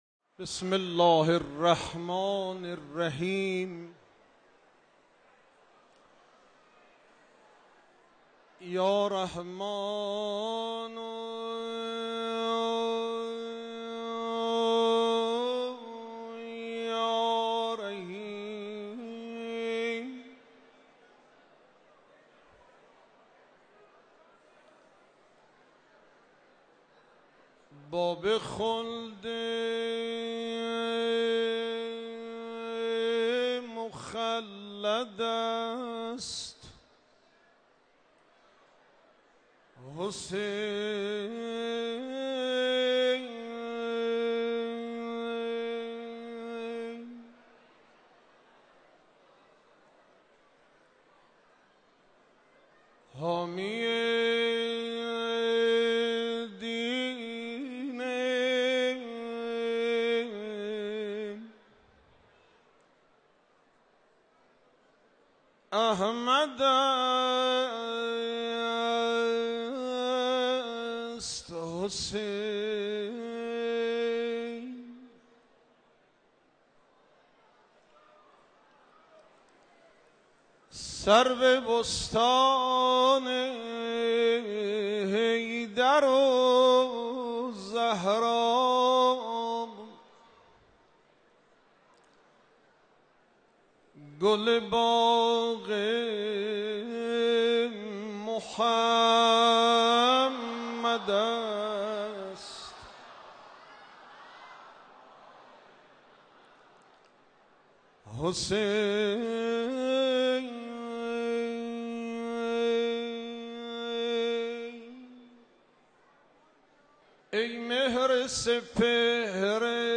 روضه | سرو بستان حیدر و زهرا گل باغ محمد است
حسینیه امام خمینی(ره)